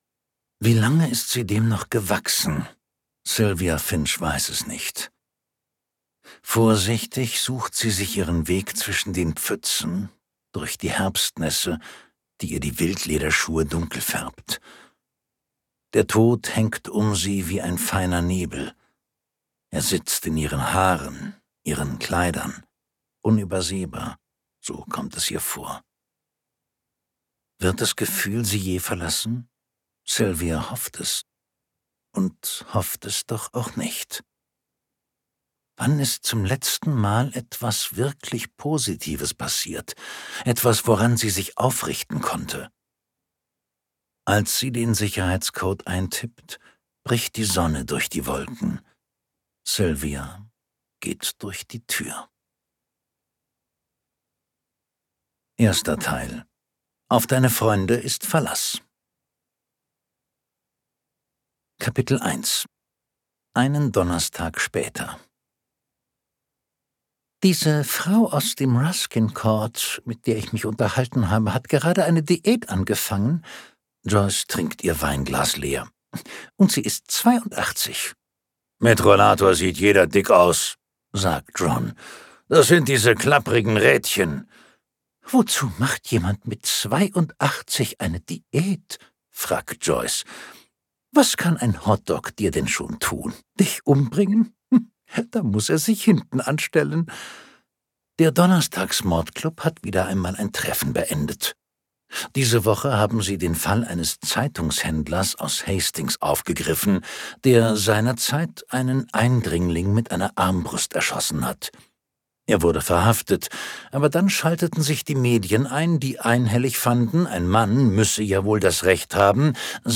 Der Mann, der zweimal starb (DE) audiokniha
Ukázka z knihy